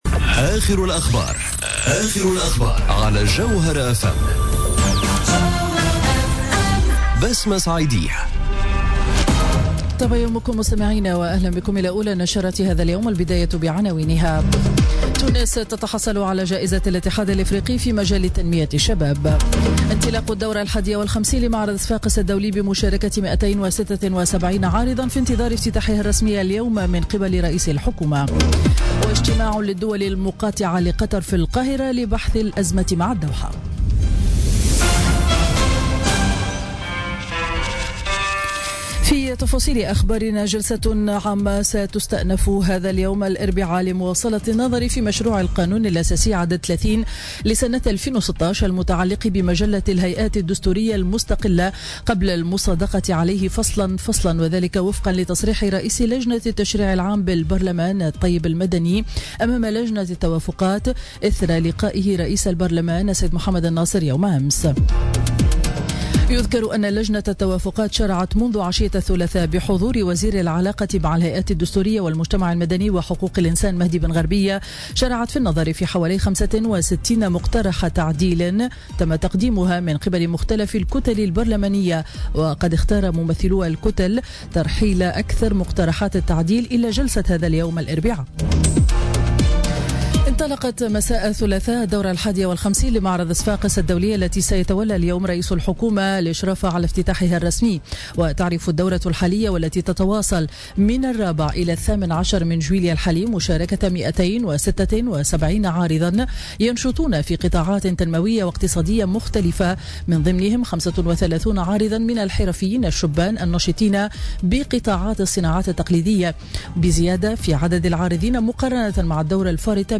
نشرة أخبار السابعة صباحا ليوم الإربعاء 5 جويلية 2017